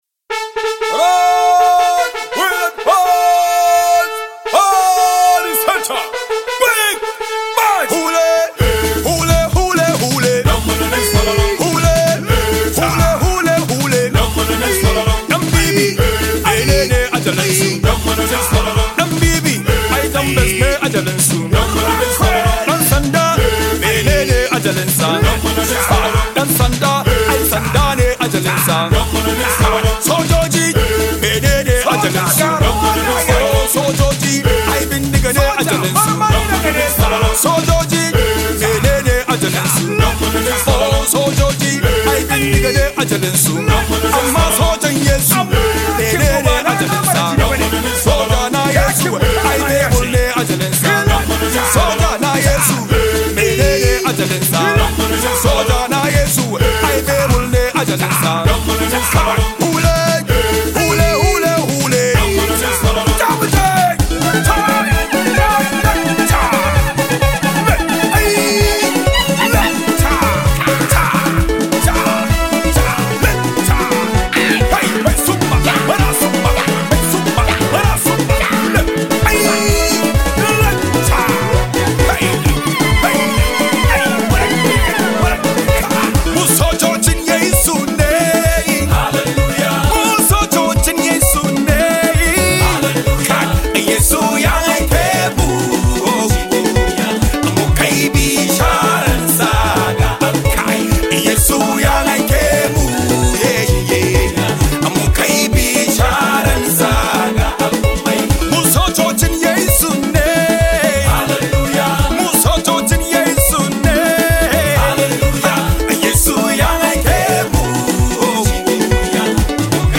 Popular Nigerian gospel music
Hausa medley